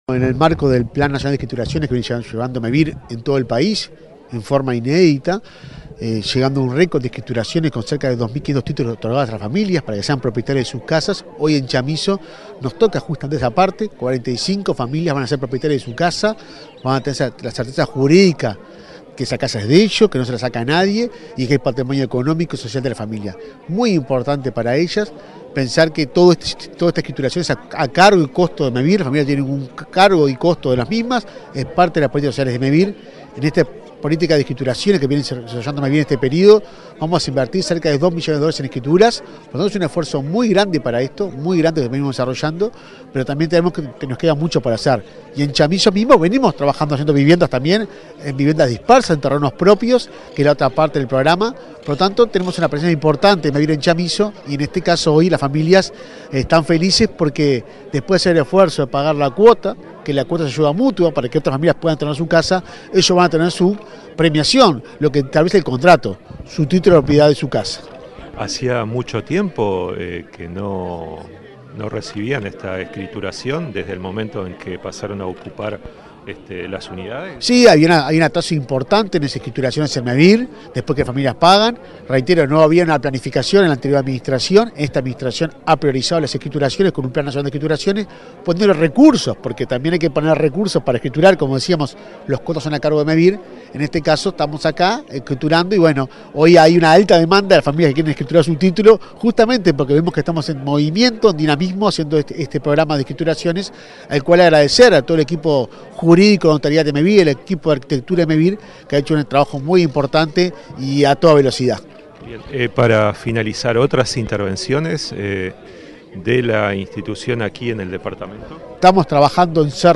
Entrevista al presidente de Mevir, Juan Pablo Delgado
Entrevista al presidente de Mevir, Juan Pablo Delgado 08/08/2024 Compartir Facebook X Copiar enlace WhatsApp LinkedIn El presidente de Mevir, Juan Pablo Delgado, dialogó con Comunicación Presidencial en Florida, donde participó de la escrituración de 45 viviendas en la localidad de Chamizo.